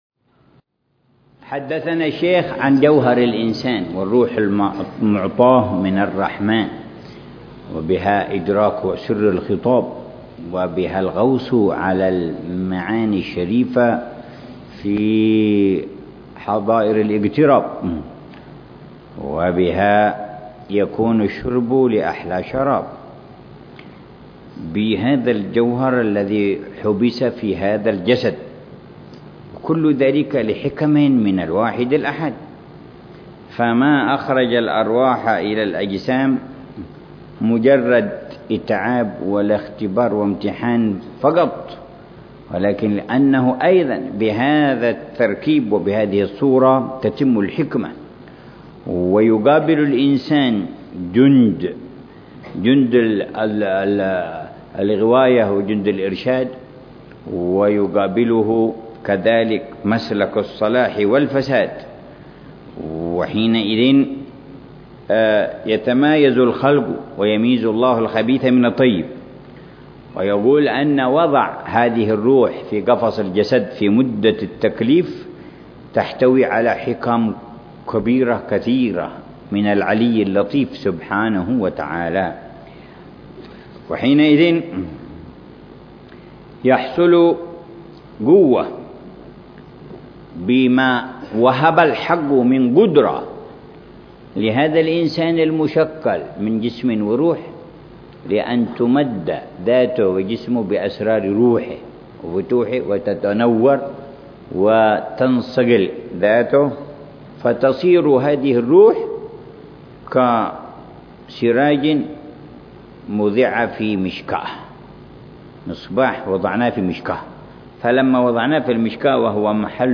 شرح الحبيب عمر بن محمد بن حفيظ لرشفات أهل الكمال ونسمات أهل الوصال.